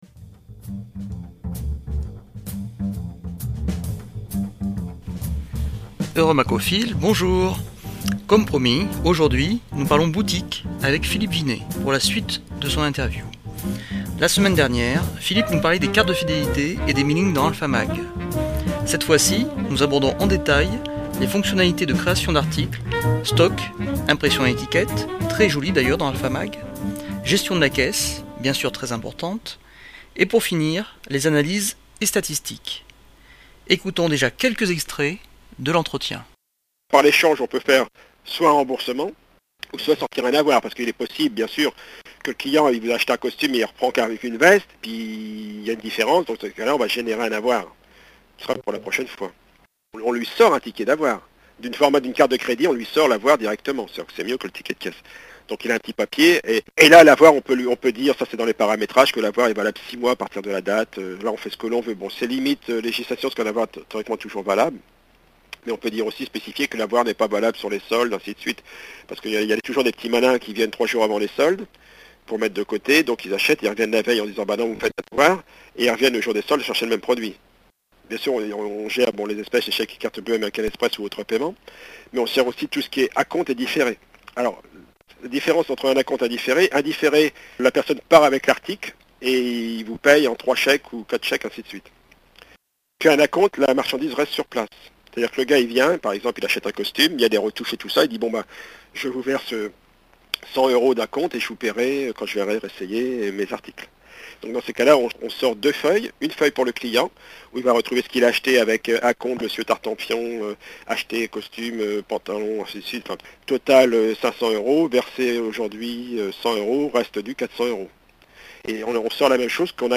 AlphaMag�: deuxi�me partie de l'interview